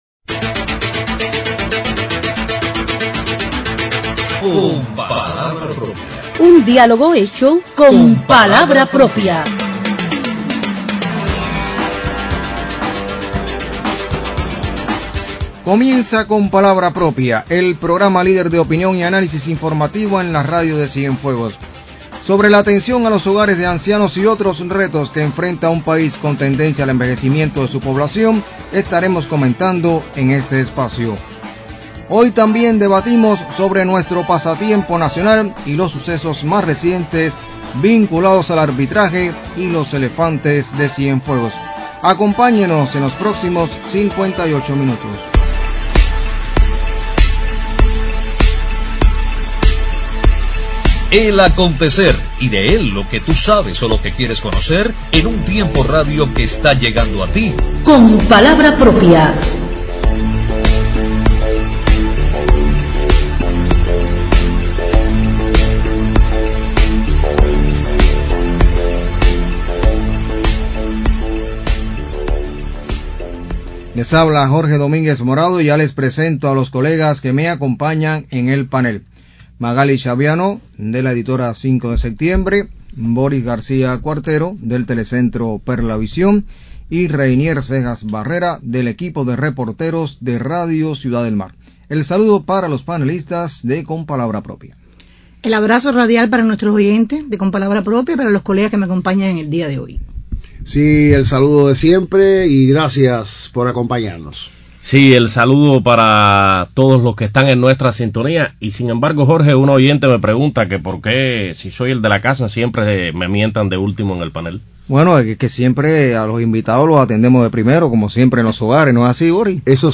De los desafíos que impone la tendencia al envejecimiento de la población cubana comentan los panelistas de Con palabra propia este 4 de mayo.